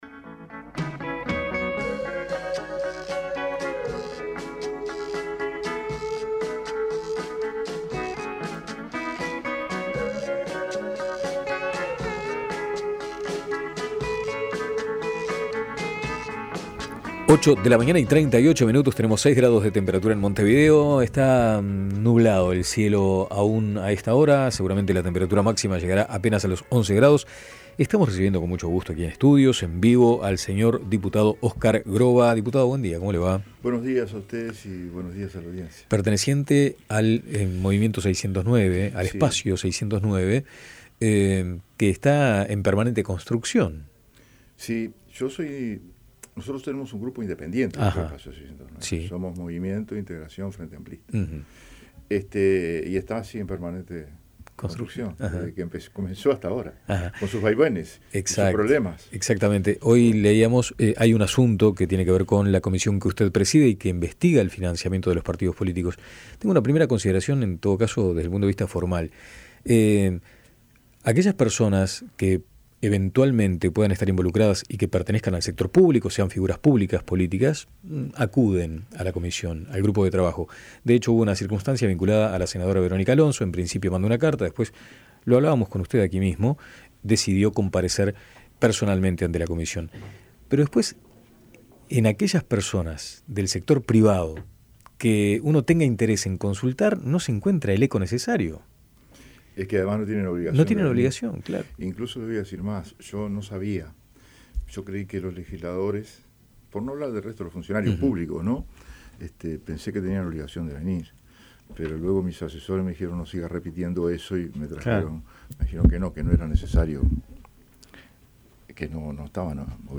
Con respecto a la citación del ex presidente José Mujica al Parlamento por su vínculo con Tenfield, el legislador manifestó en La Mañana de El Espectador que le parece bien que concurra, pero señaló que no existe relación con la empresa aunque esa cuestión la deberá aclarar el actual senador.
Descargar Audio no soportado Escuche la entrevista completa: Descargar Audio no soportado